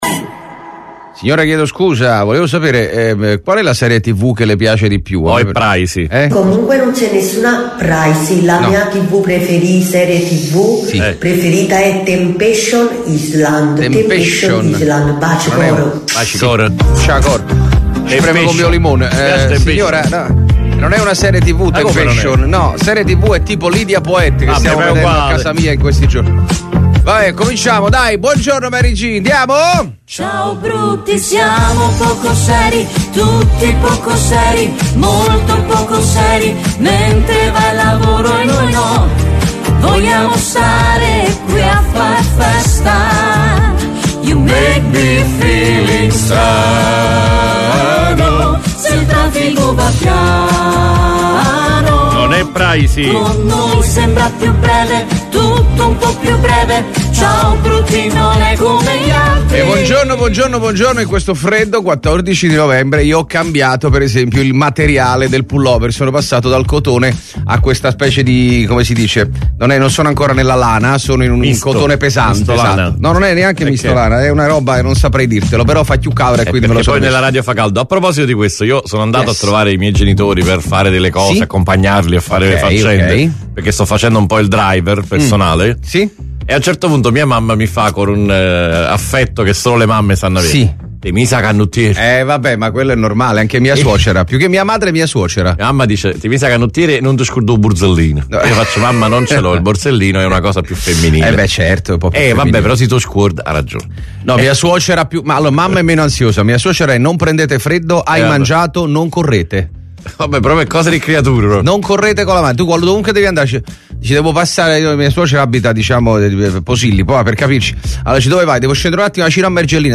IL MORNING SHOW DI RADIO MARTE